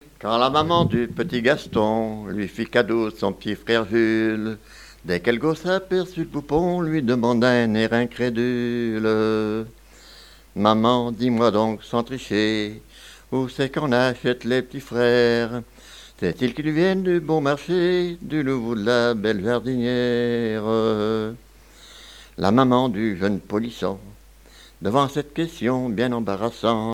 Genre strophique
danses à l'accordéon diatonique et chansons
Pièce musicale inédite